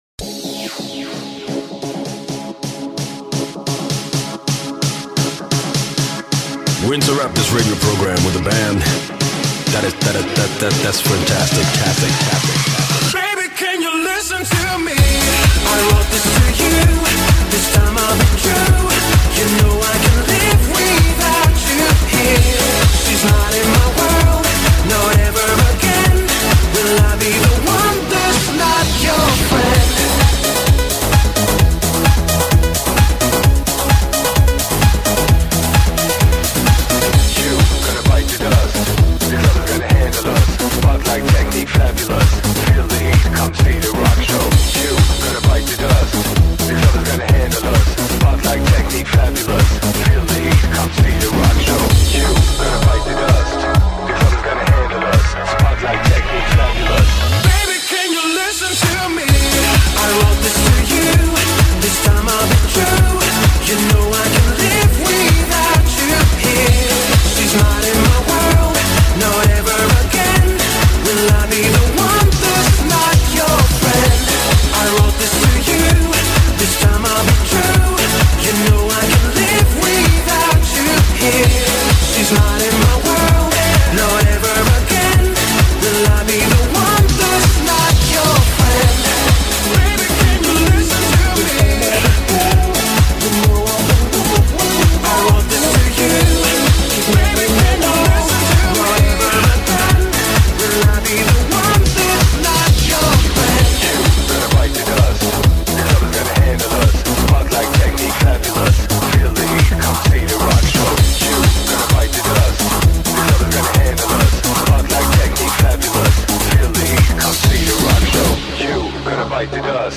Дискотечная музыка, очень класная.
класно потанцевать под неё.